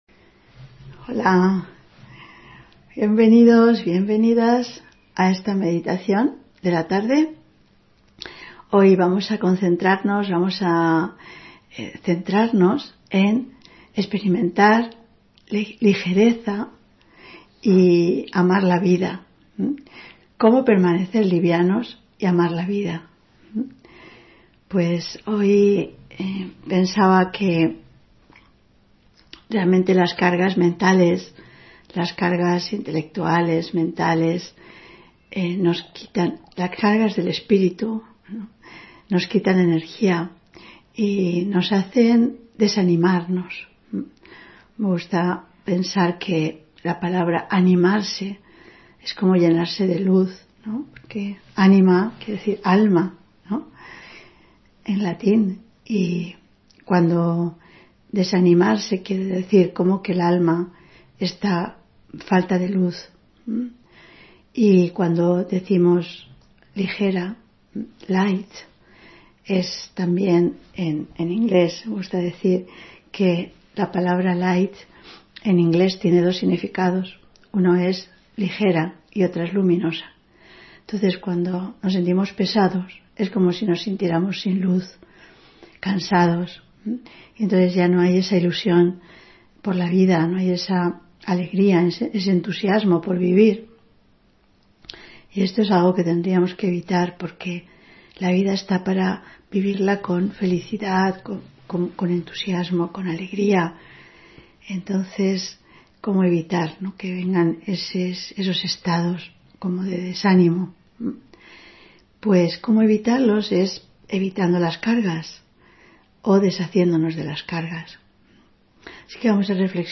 Audio conferencias